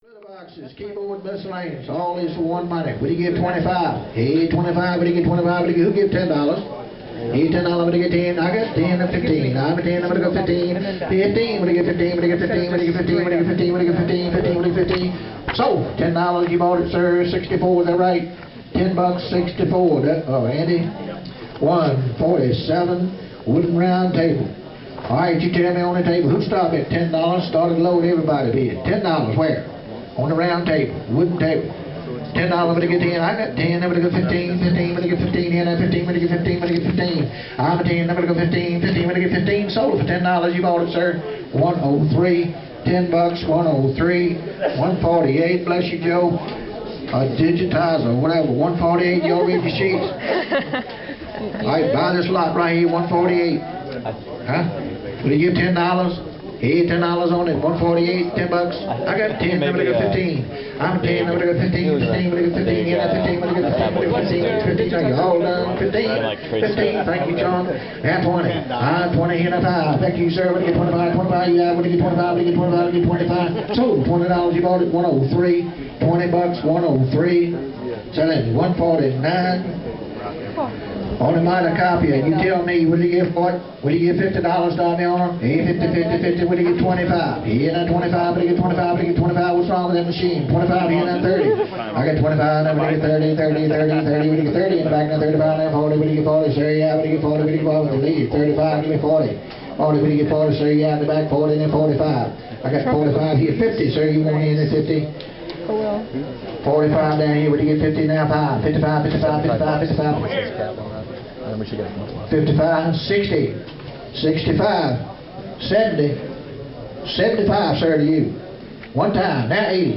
Look at those auctioneers go! They were on a big cart with battery powered speakers and just rolled down the length of the building as the 700-some item auction progressed.
here's what the auction sounded like presented as a hube (14MB) WAV sound file.